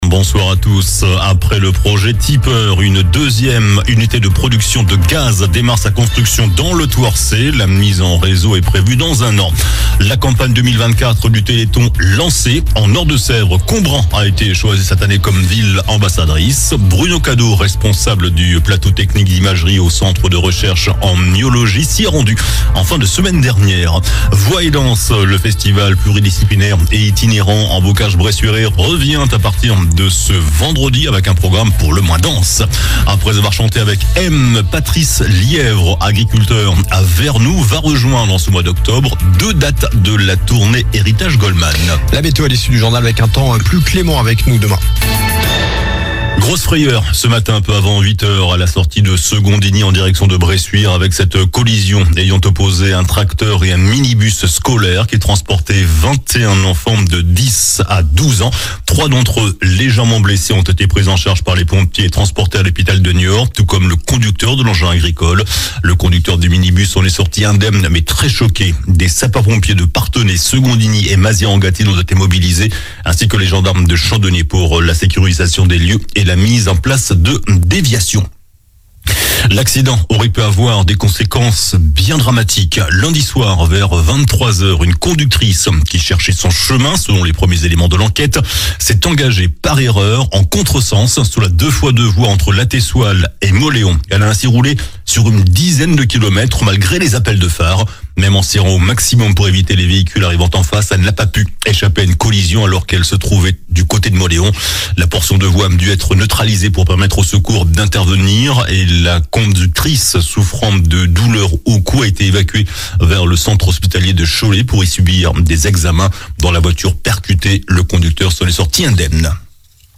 JOURNAL DU MARDI 01 OCTOBRE ( SOIR )